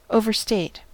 Ääntäminen
Synonyymit exaggerate overcalculate Ääntäminen US UK : IPA : /ˌəʊ.vəˈsteɪt/ US : IPA : /ˌoʊ.vɚˈsteɪt/ Haettu sana löytyi näillä lähdekielillä: englanti Määritelmät Verbit To exaggerate ; to state or claim too much.